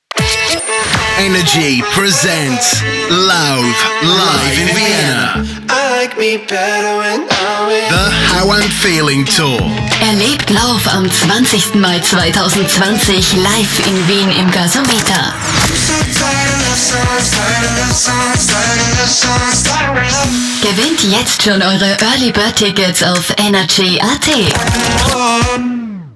Hier finden Sie eine kleine Auswahl unserer Sprecher-Demos: